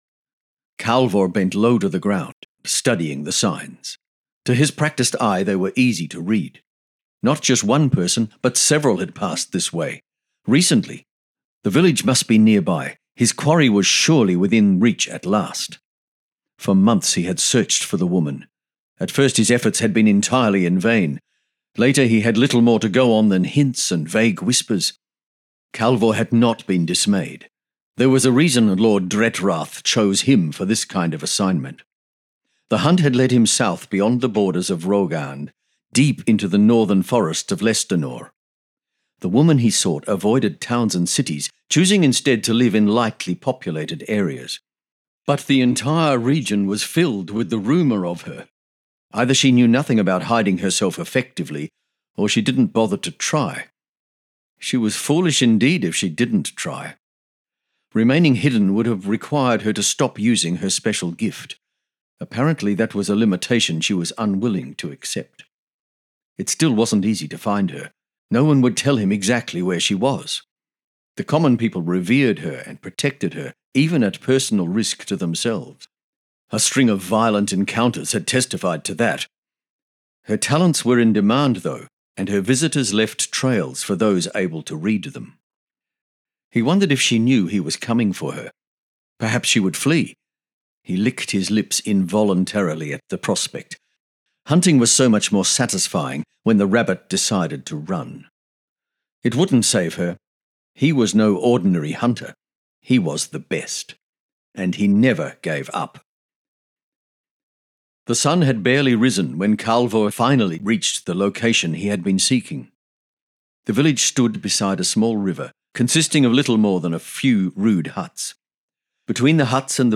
Another listener says, "The narrator speaks with great clarity, energy and emotion."